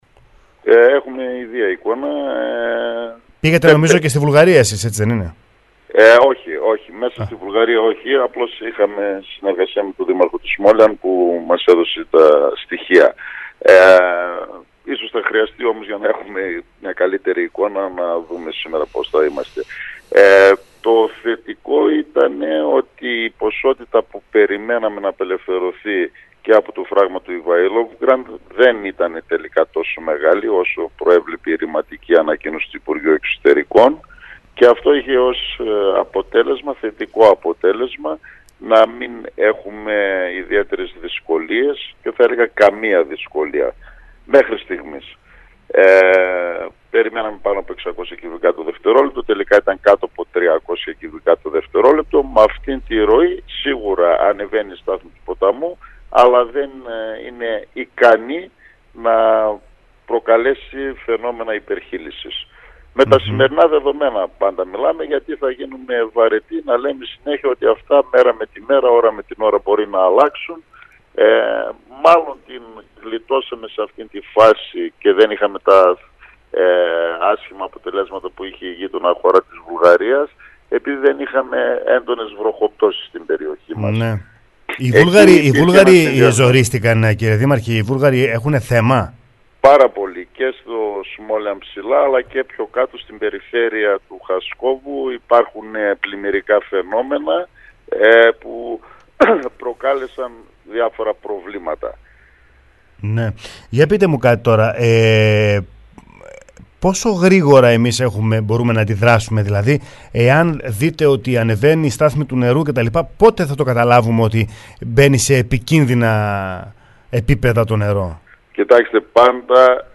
το ραδιόφωνο sferikos 99,3 σήμερα το πρωί